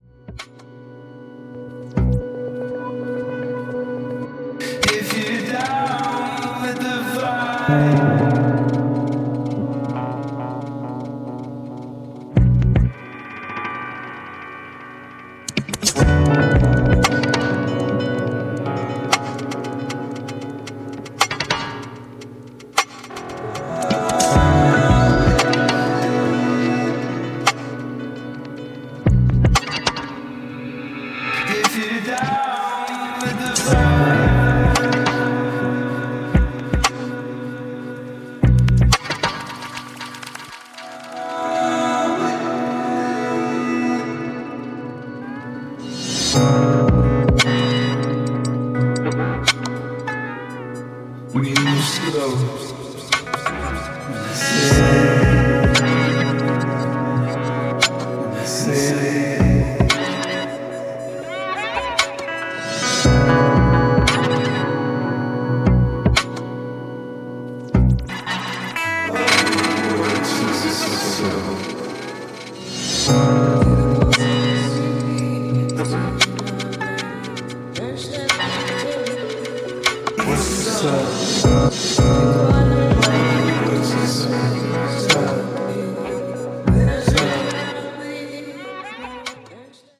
とことん、渋い一枚。